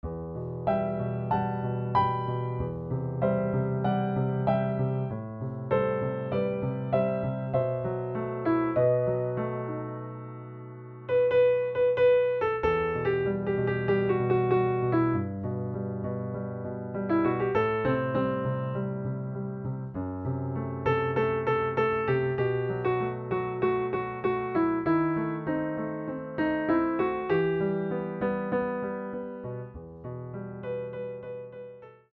Piano
Tono original: Em